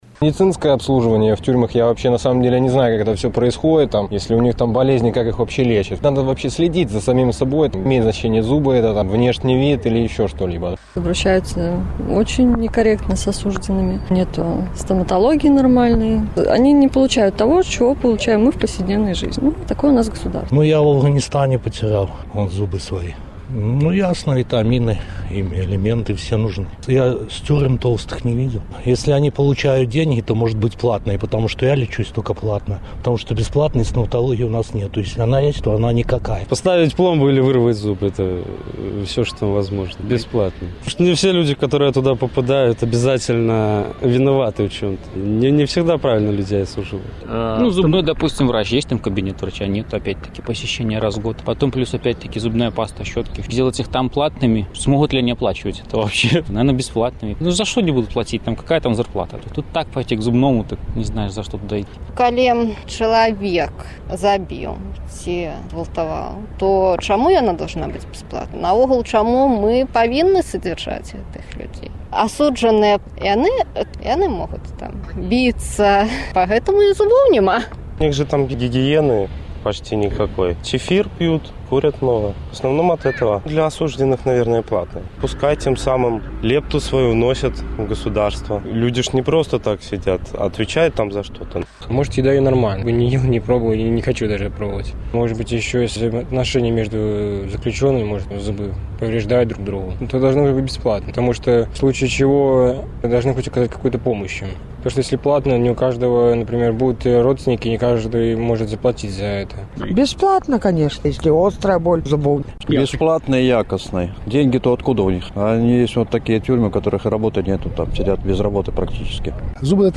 Якую стаматалягічную дапамогу трэба аказваць беларускім зьняволеным? Адказваюць магілёўцы